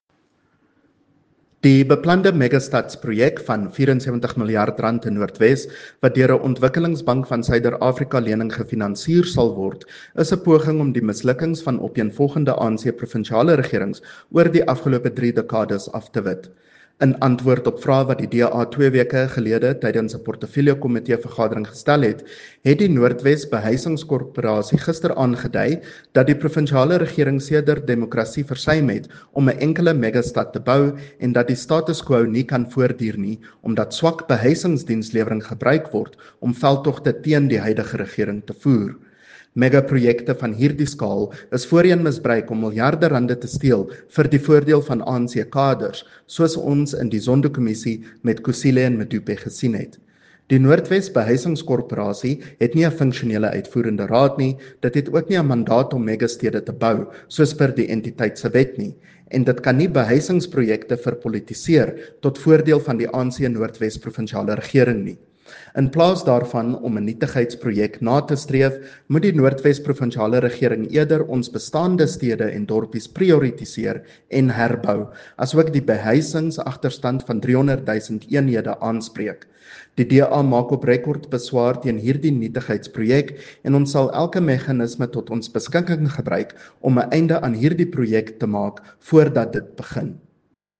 Note to Broadcasters: Please find linked soundbites in
Afrikaans by CJ Steyl MPL.